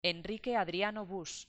HENRIQUE Adriano BussEnríke Adriáno Bus